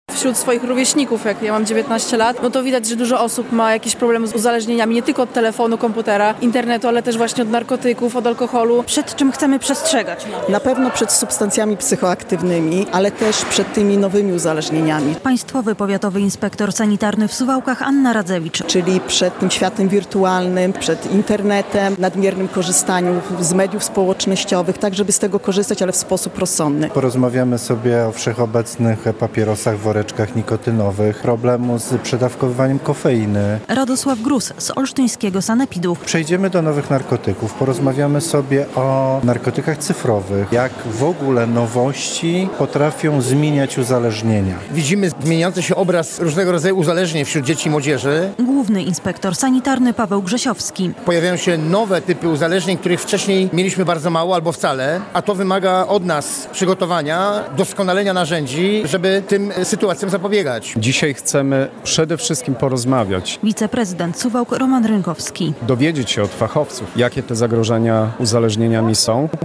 O uzależnieniach na konferencji w Suwałkach